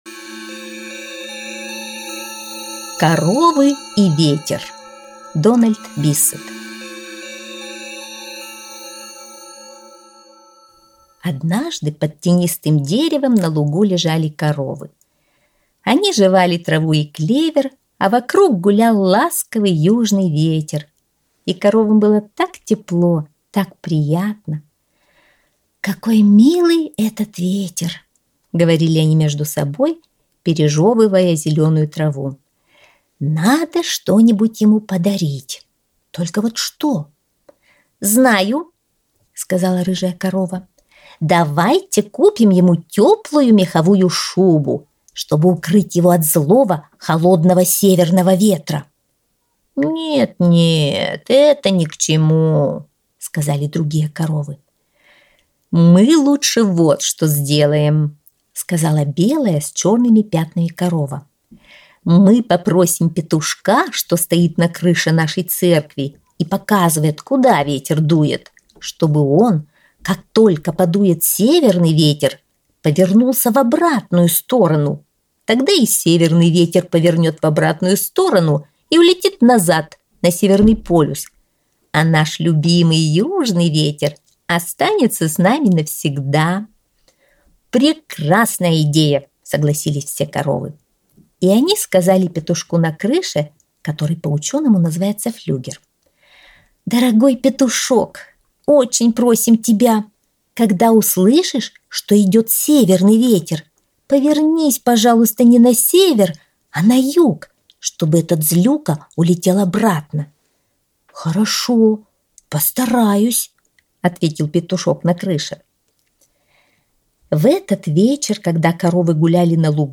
Аудиосказка «Коровы и ветер»